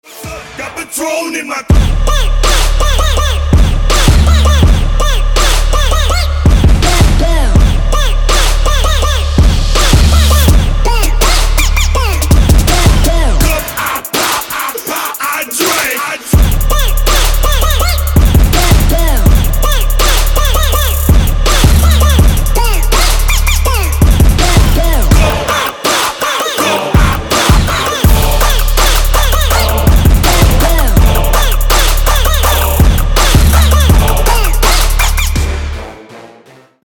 • Качество: 224, Stereo
Trap
Bass
трэп